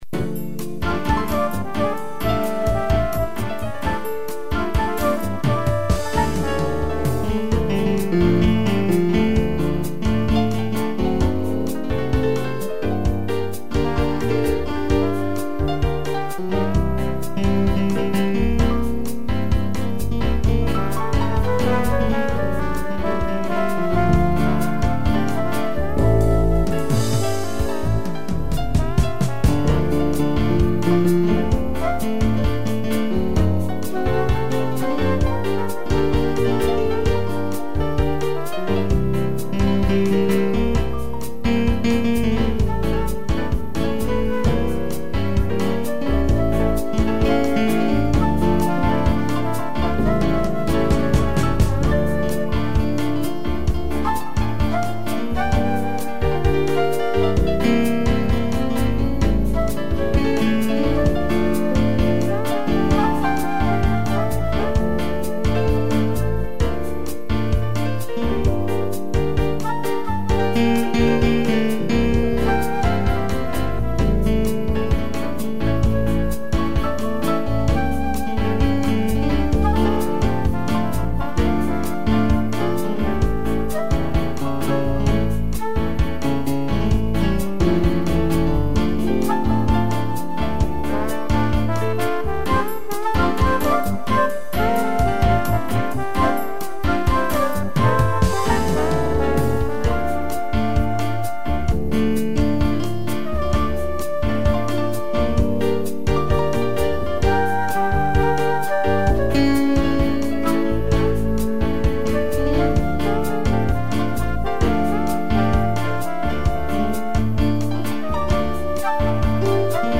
piano, trombone e flauta
(instrumental)